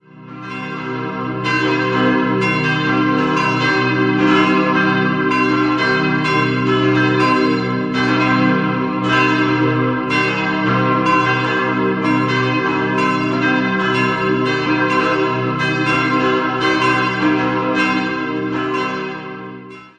Diese erhielt in der ersten Hälfte des 15. Jahrhunderts ihr heutiges Aussehen und wird seit der Reformationszeit von beiden Konfessionen genutzt. 4-stimmiges Geläute: c'-e'-g'-c'' Alle Glocken wurden im Jahr 1864 von der Firma Rüetschi in Aarau gegossen.